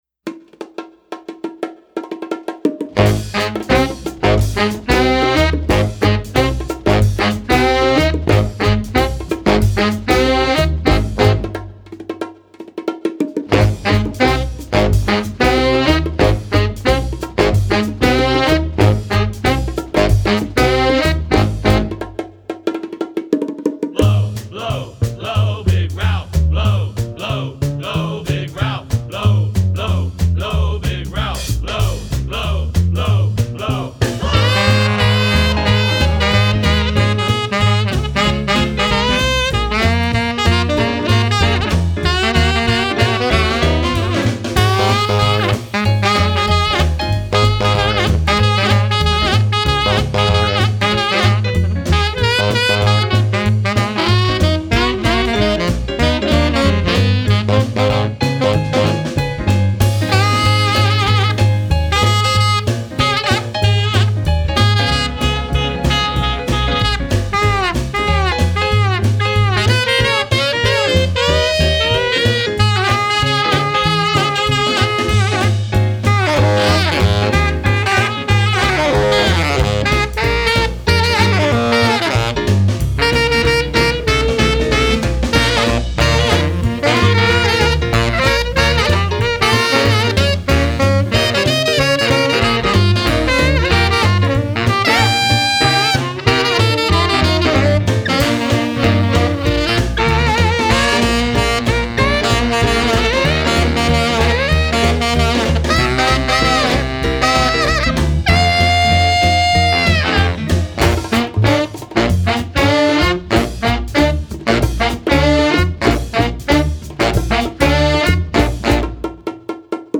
soundscapes
saxophone